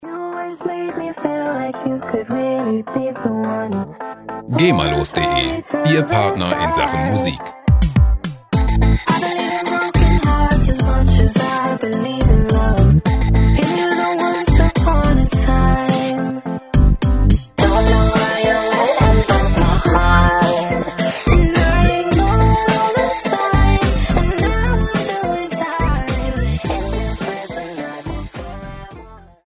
Pop Loops GEMAfrei
Musikstil: Pop
Tempo: 106 bpm